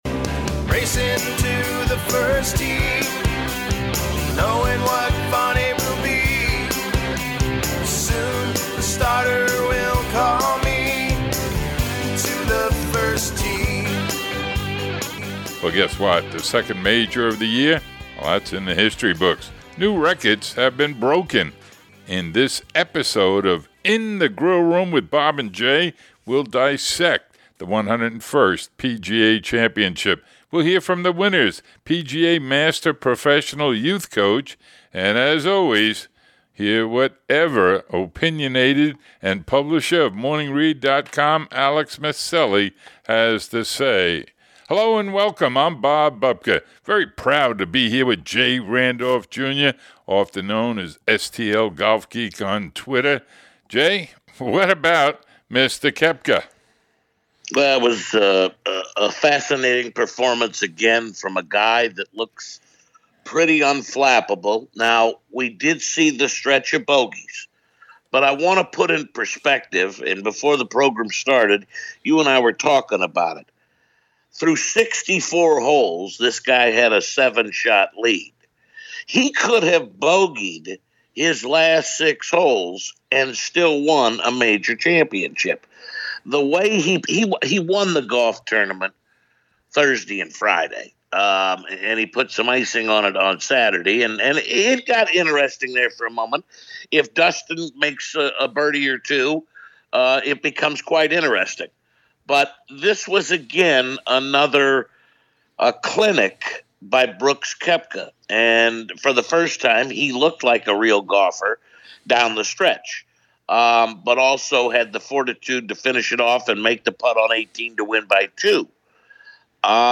Player audio: Koepka, D.Johnson, and Matthew Wallace.